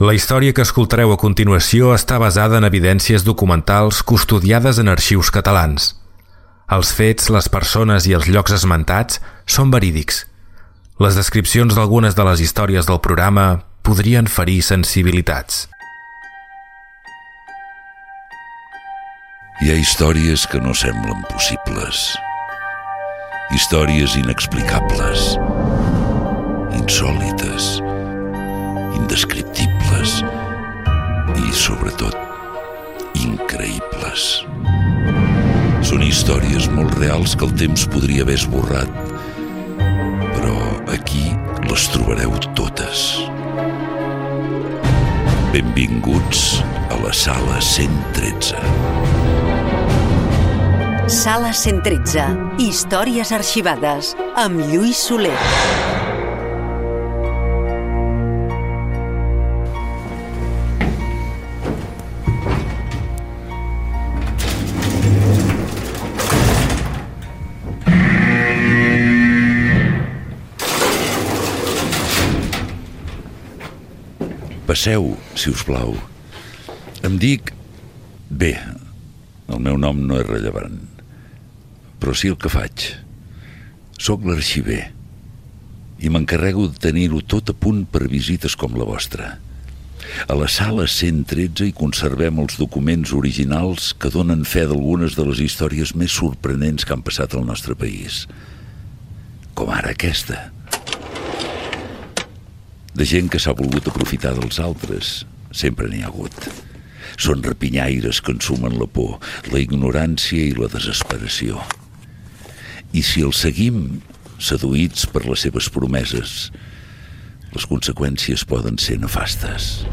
Careta del programa i fragment inicial del capítol "El bruixot de Terrassa"
Entreteniment